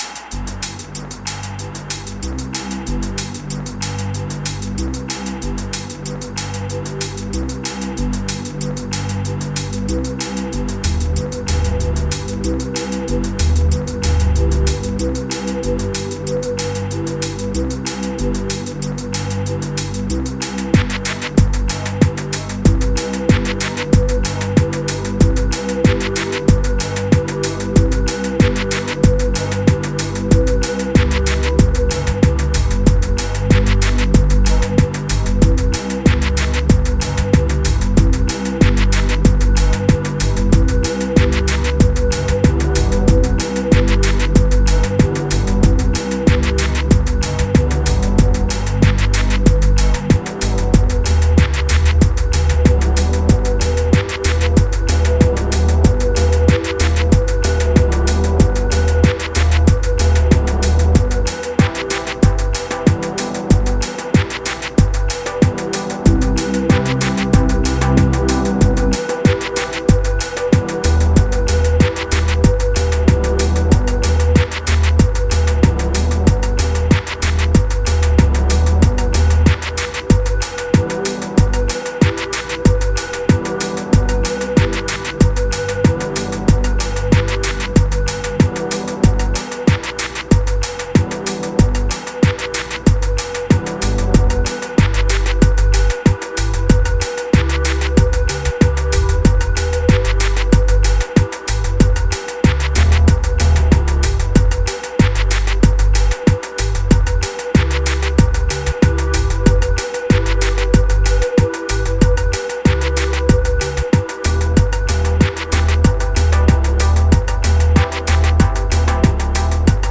electronic dubity energism velo-city